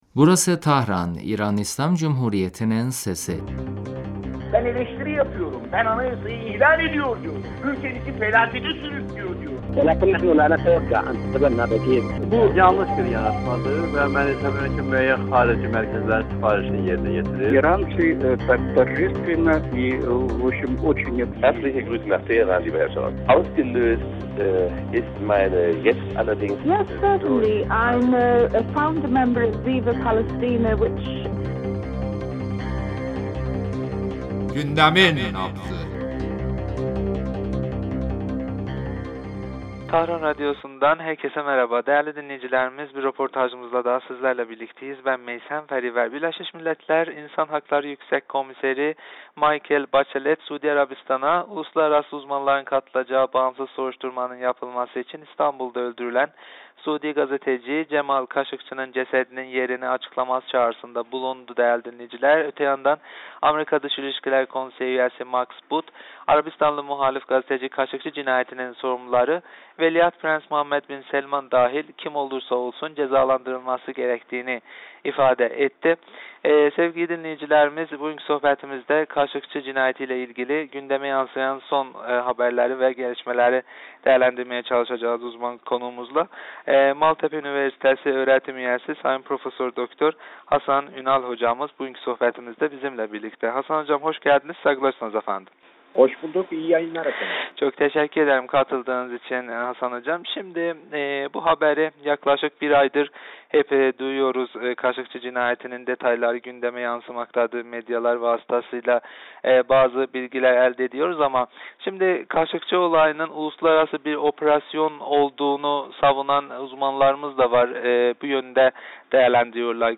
telefon görüşmesinde Suudi gazetecinin kaderi ve onunla ilgili işlenen cinayet üzerinde konuştuk.